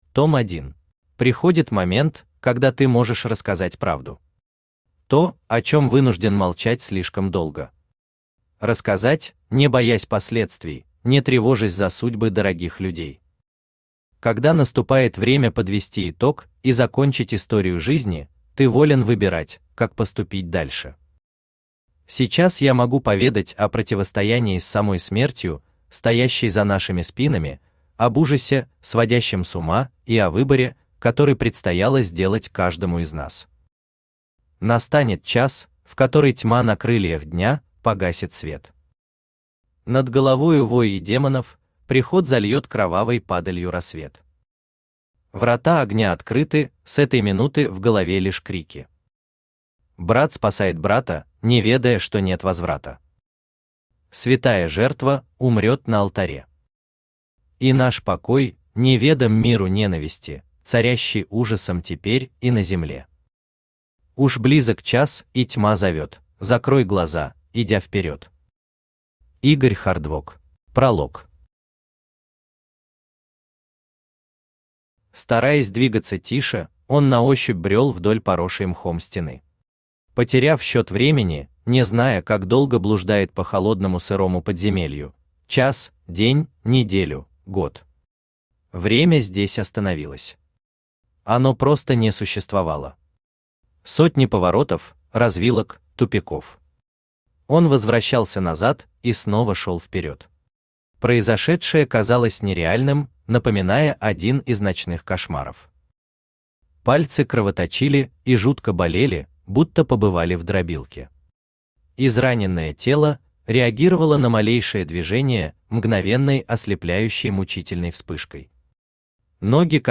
Аудиокнига Шелест. Том 1 | Библиотека аудиокниг
Aудиокнига Шелест. Том 1 Автор И. Коулд Читает аудиокнигу Авточтец ЛитРес.